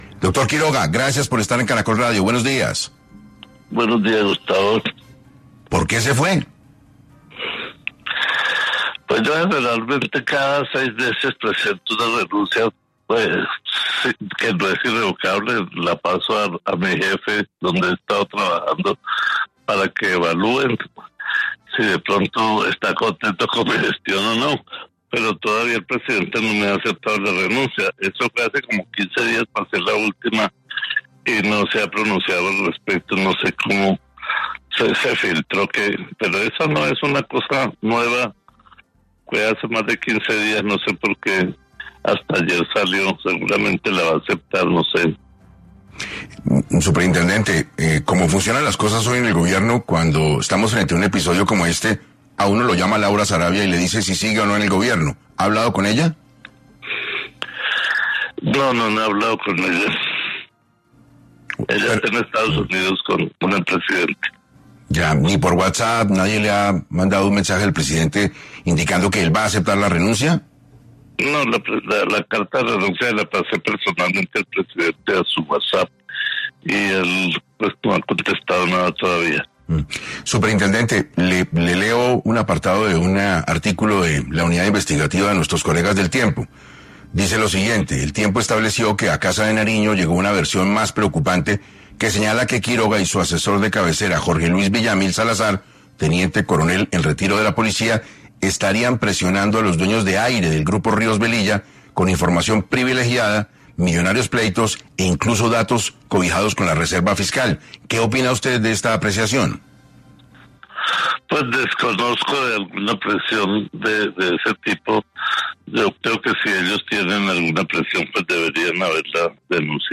En 6AM de Caracol radio estuvo Dagoberto Quiroga, exsuperintendente de Servicios Públicos, para hablar sobre cuál fue el verdadero motivo de su salida de la dirección de la Superintendencia de Servicios Públicos.